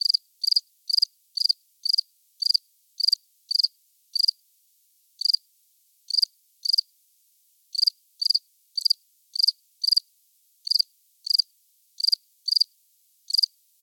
insectnight_17.ogg